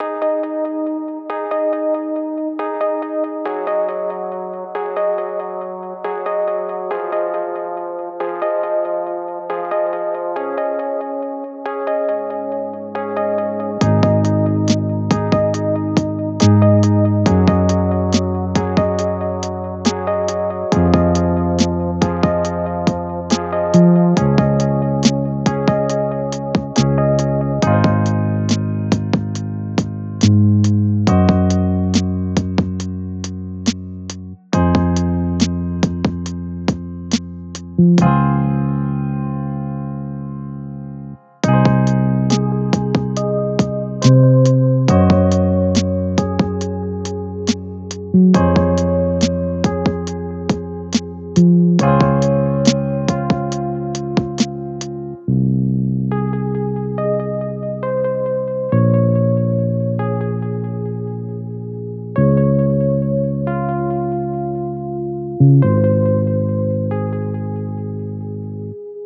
Loopable calm floating island music.